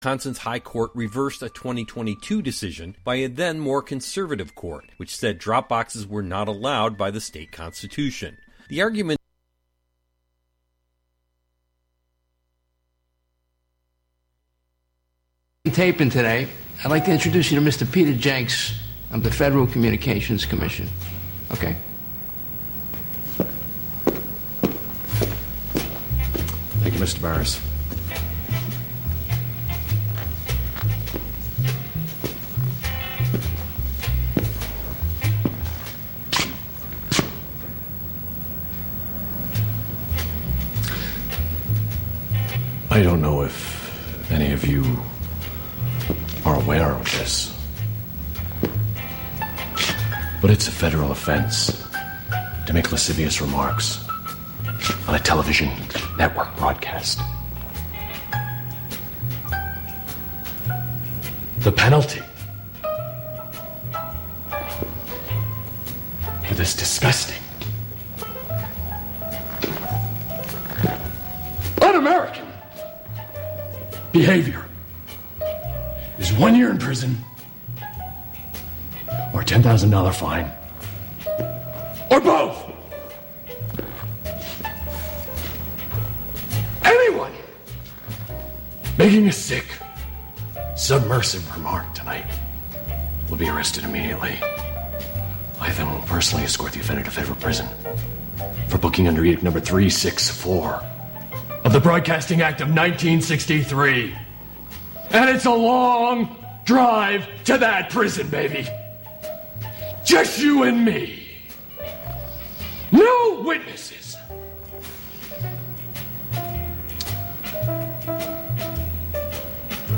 Well, if you are listening LIVE, then you may very well get to ask that question--as a call-in to the show.